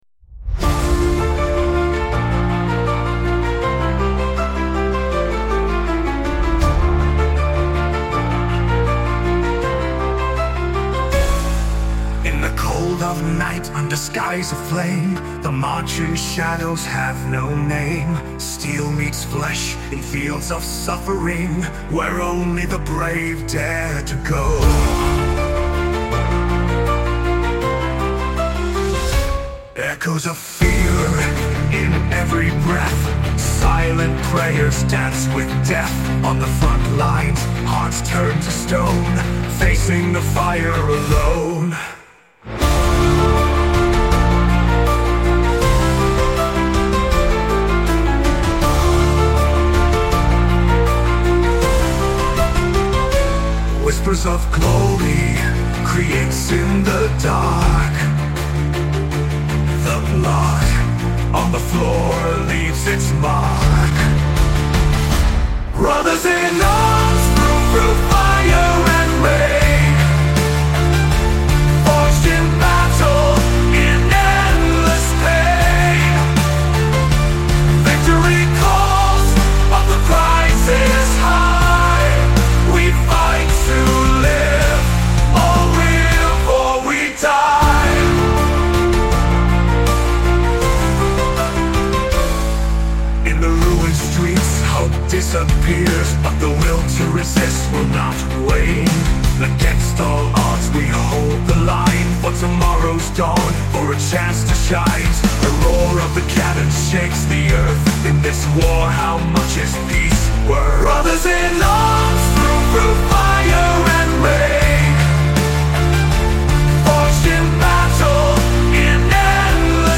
EstiloMetal Melódico